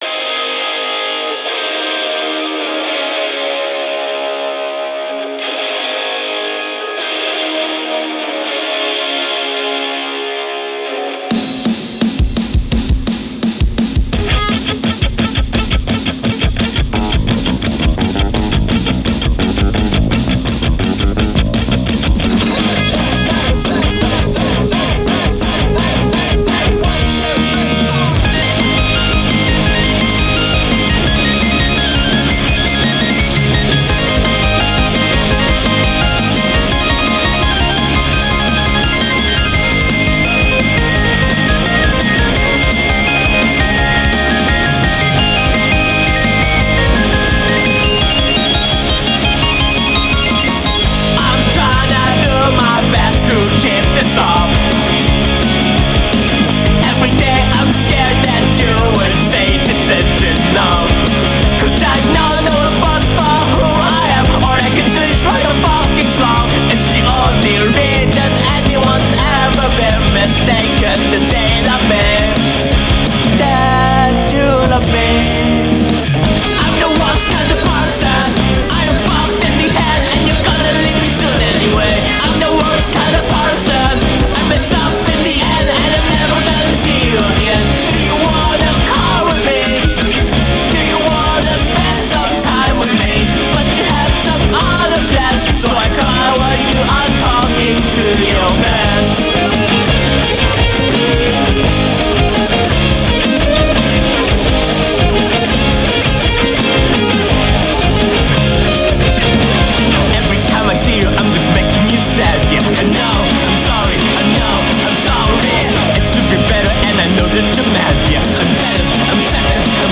It's an emo album about being a sad bunny.
Guitar, bass, bg vocals